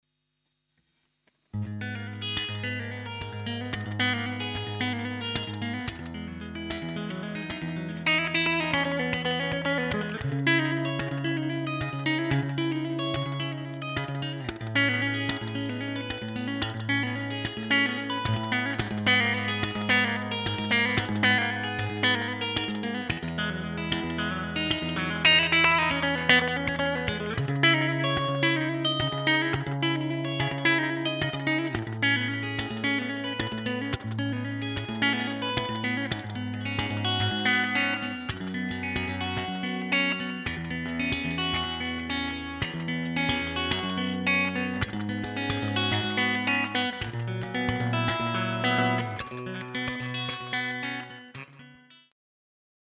To ensure the amp’s true voice shines through, recordings were made using a Shure SM57 mic on a Marshall cabinet, alongside a direct line from the amp’s balanced SM57 mic sim output.
We kept effects minimal to let the amp speak for itself — just a hint of reverb, light delay, and a short wah pedal section.”
Sabre_Clean_ch_demomp3.m4a